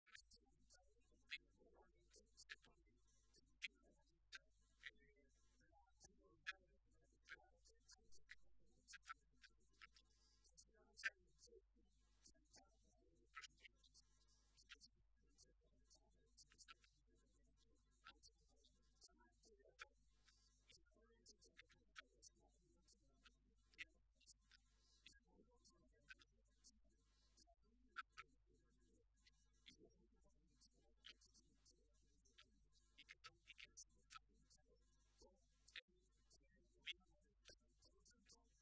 José Molina, viceportavoz del Grupo Parlamentario Socialista
Cortes de audio de la rueda de prensa